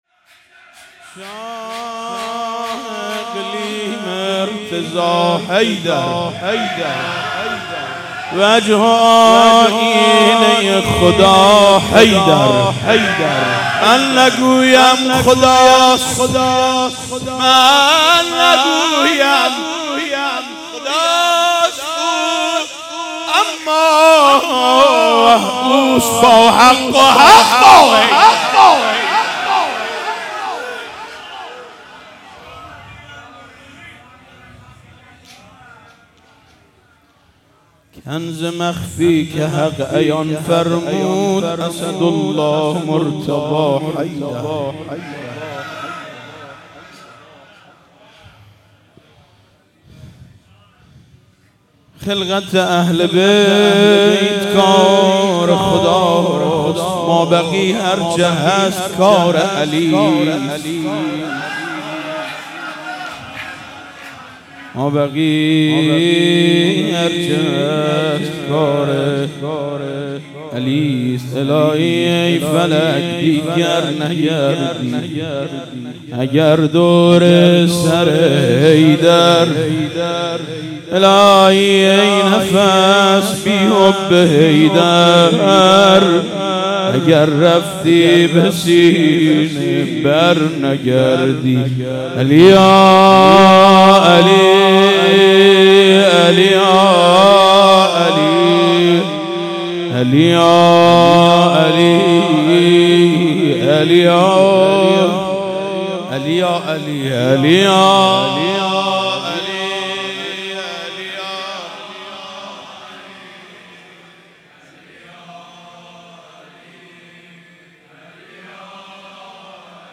مراسم هفتگی/15آذر97